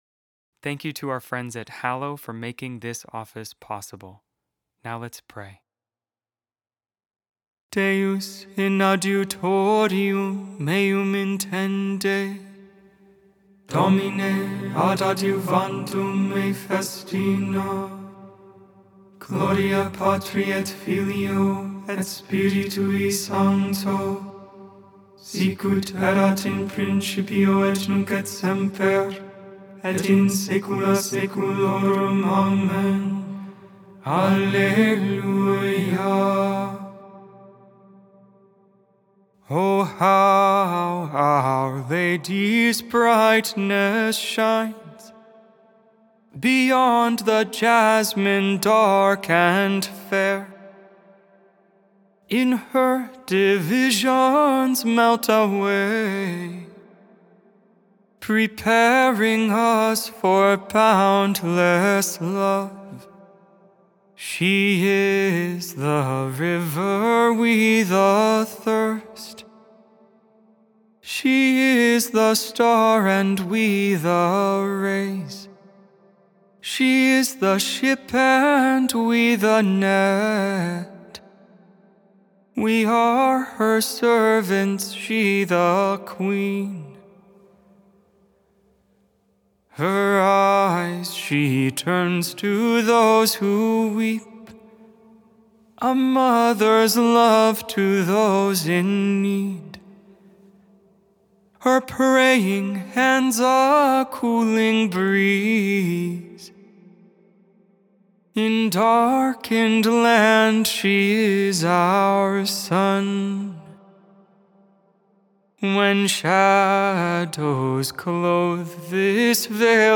Vespers, Evening Prayer for the 2nd Thursday in Advent, December 12th, 2025. Feast of Our Lady of Guadalupe.Made without AI. 100% human vocals, 100% real prayer.